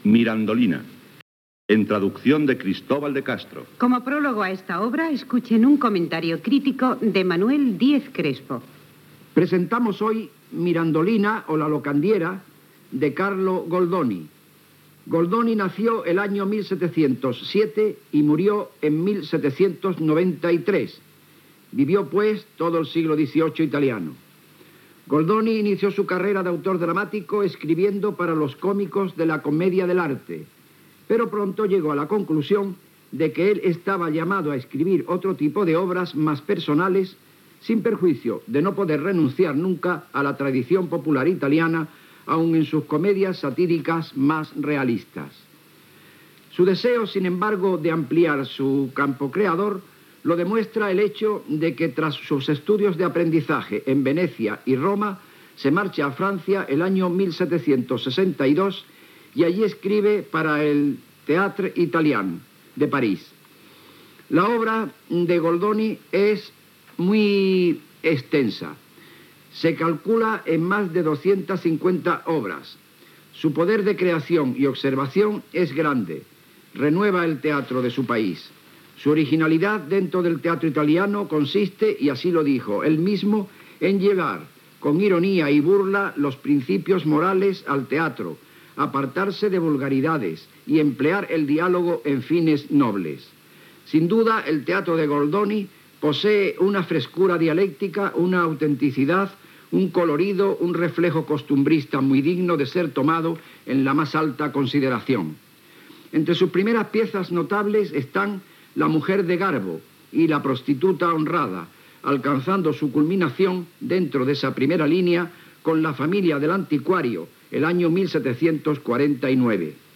Versió radiofònica de l'obra de Carlo Goldoni «Mirandolina». Presentaciò, comentari sobre Goldoni, careta del programa amb el repartiment, diàleg entre el compte i el marquès sobre els seus títols i Mirandolina
Ficció